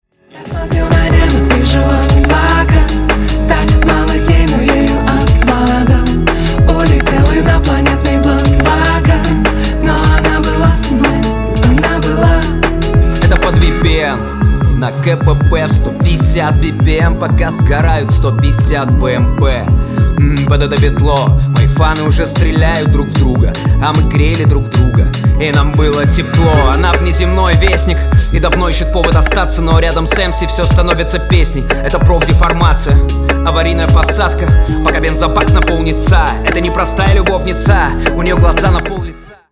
• Качество: 320, Stereo
русский рэп
качающие